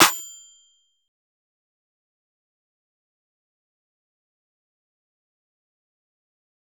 Official Trap Clap (3).wav